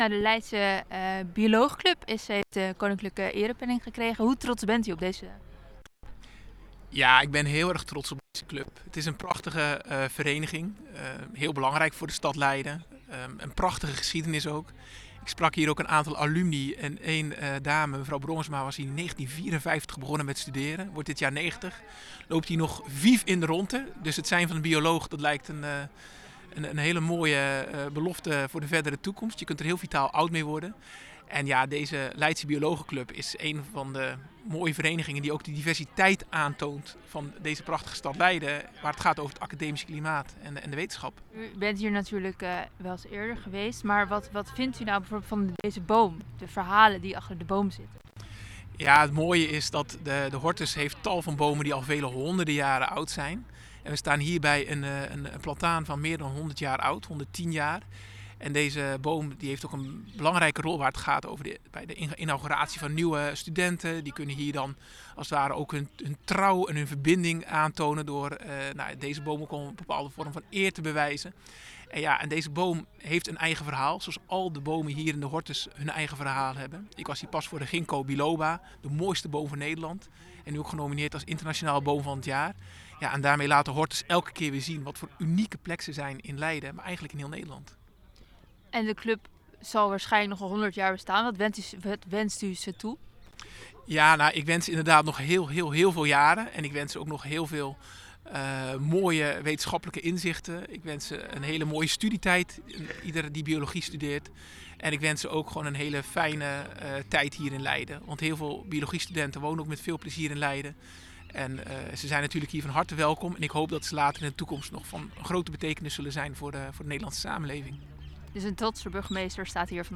interview-burgemeester-voor-lbc-_mixdown-2-1.wav